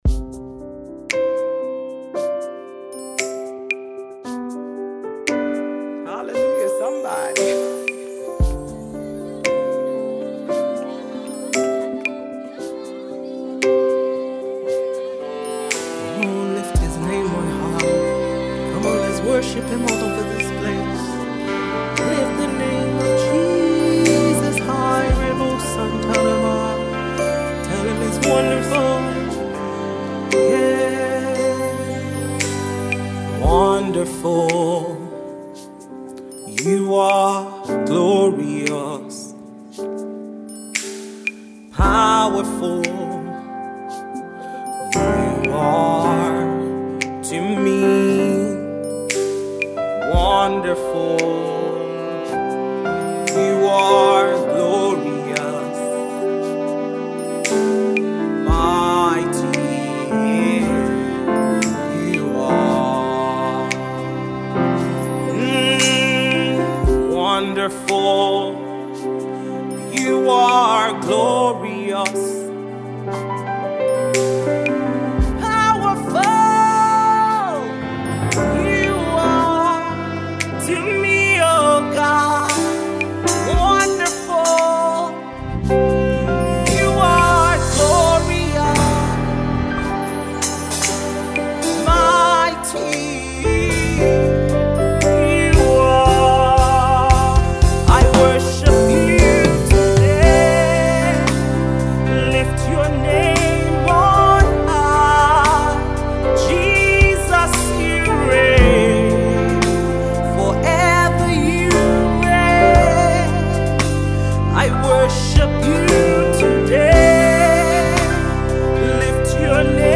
U.S. based Gospel Music Minister